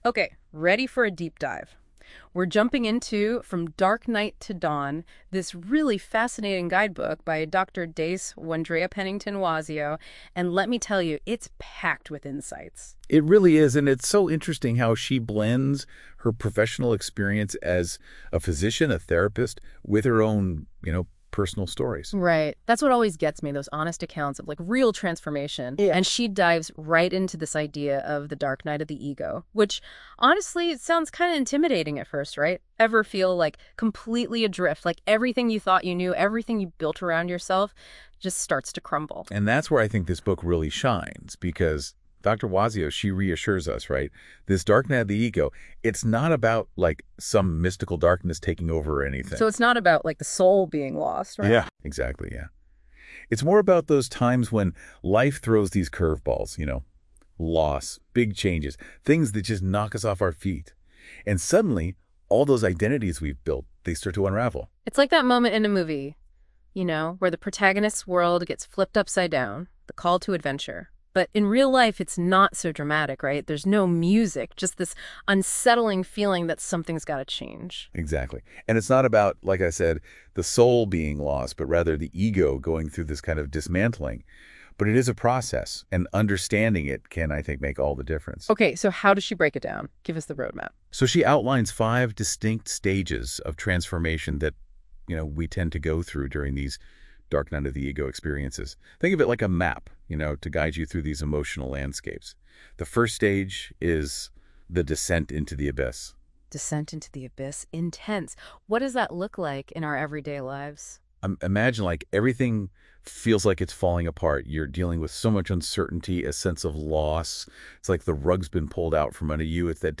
An Overview of the Dark Night to Dawn Guidebook | AI-generated Podcast!